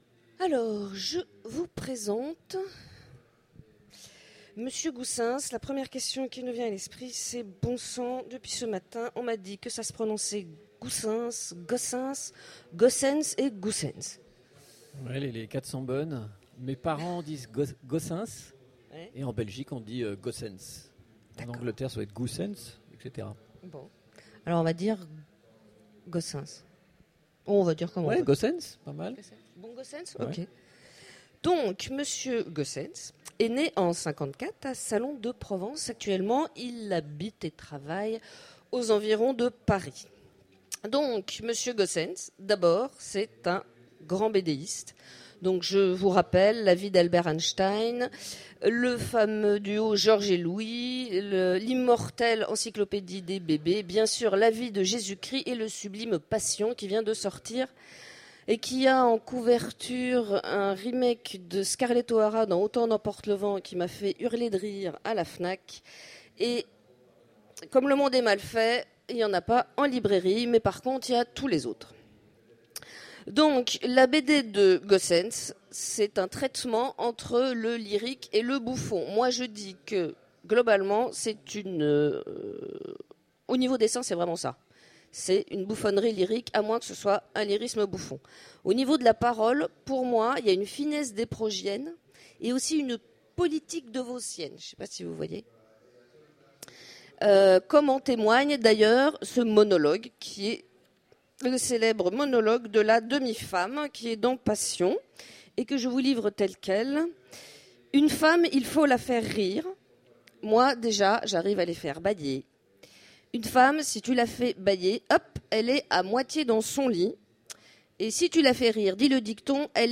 Utopiales 2014 : Rencontre avec Daniel Goossens